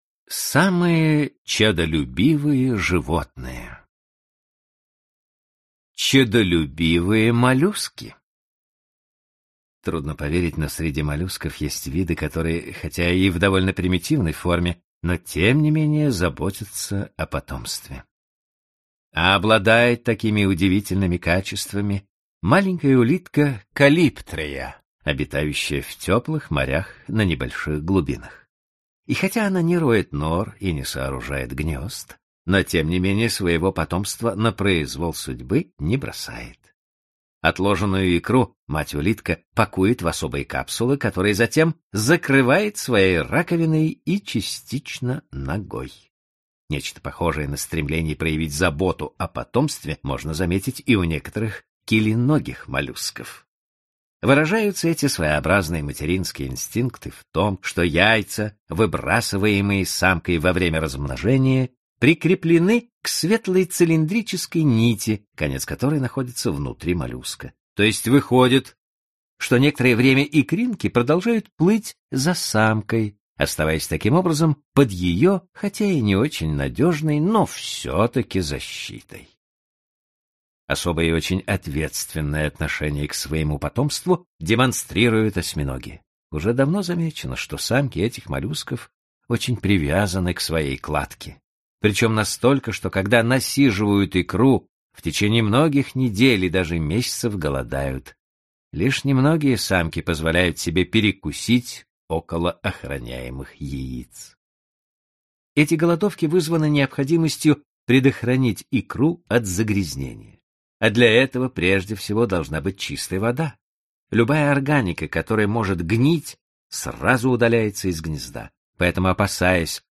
Аудиокнига Рекорды животных | Библиотека аудиокниг